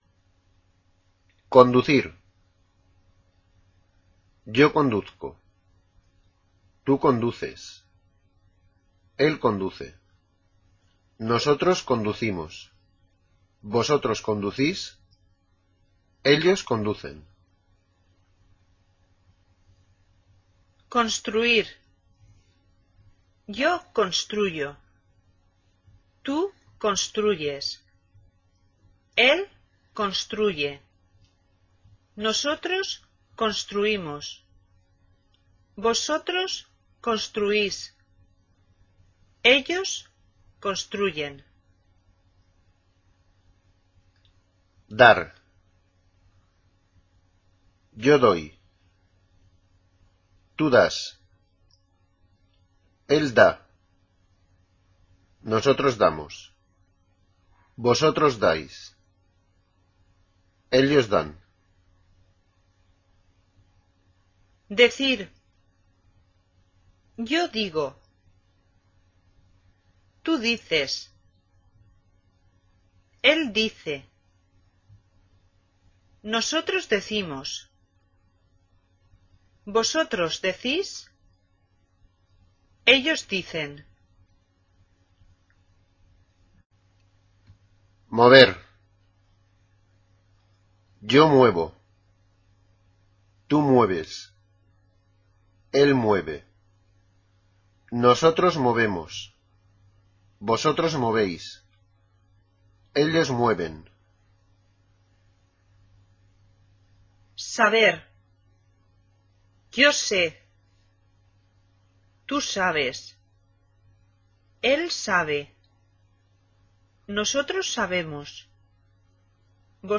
Ahora escucha la pronunciación de los verbos anteriores.